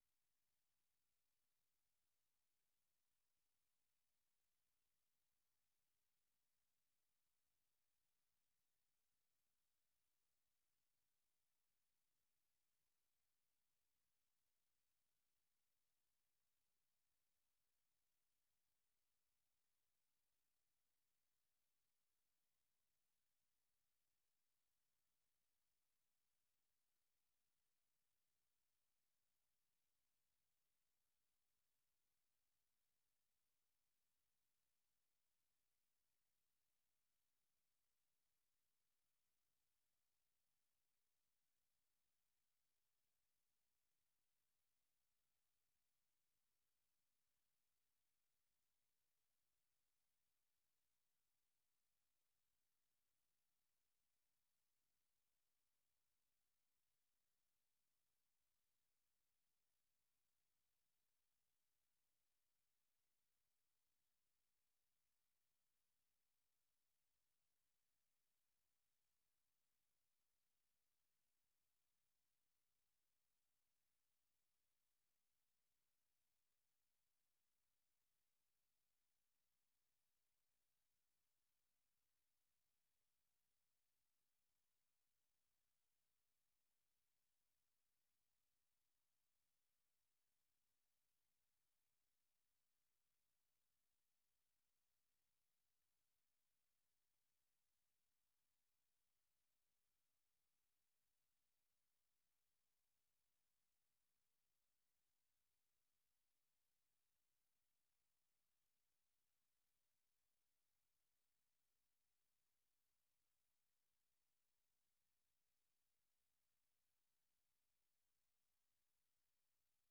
Listen Live - 粵語廣播 - 美國之音